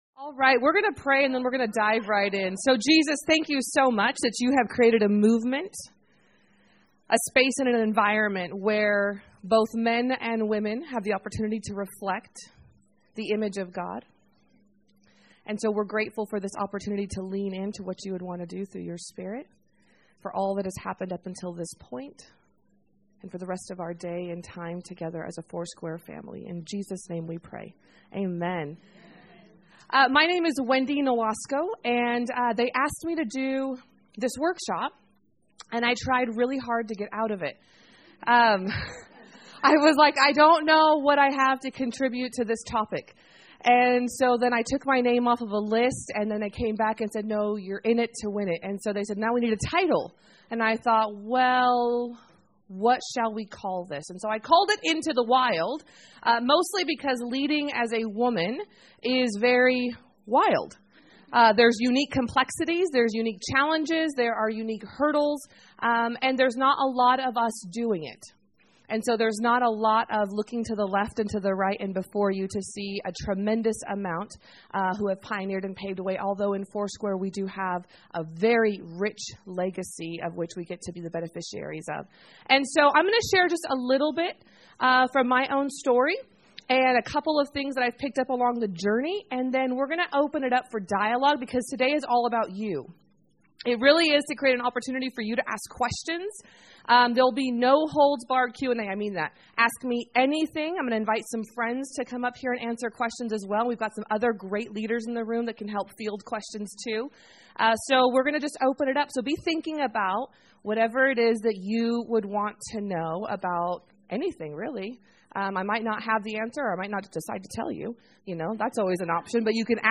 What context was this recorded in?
in this workshop recorded live at Foursquare Connection 2018.